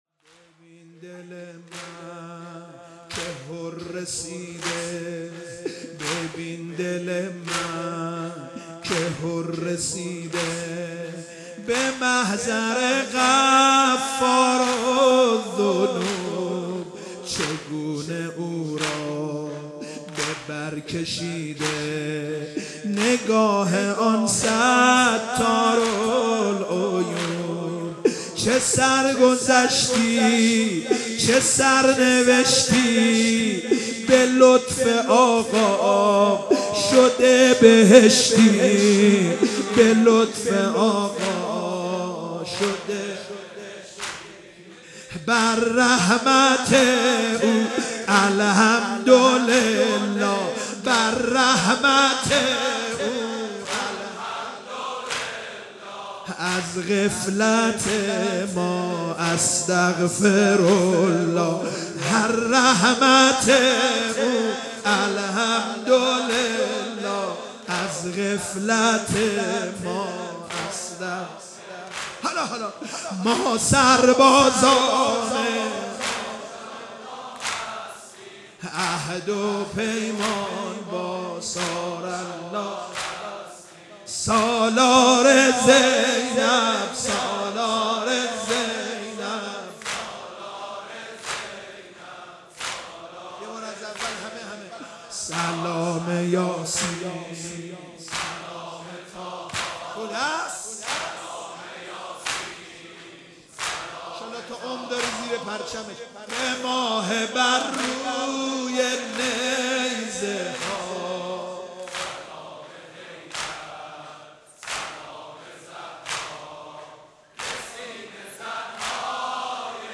شب چهارم محرم 96 - نوحه